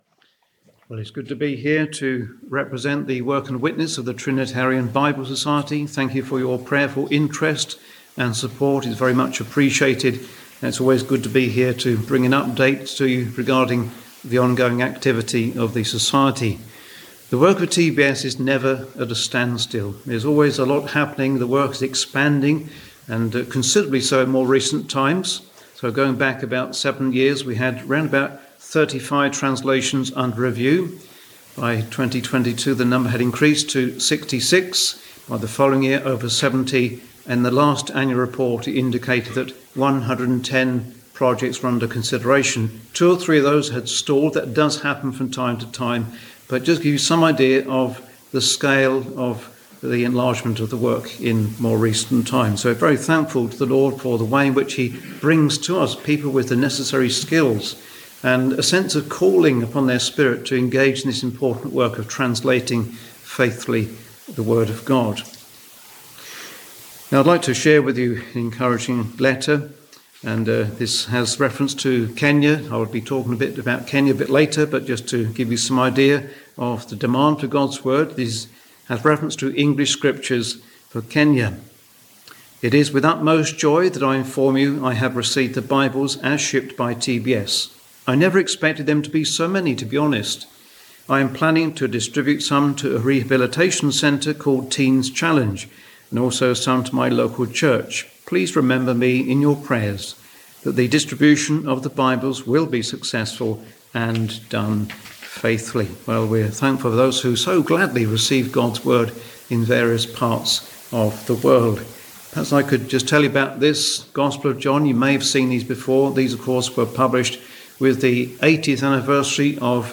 Wednesday Bible Study
Sermon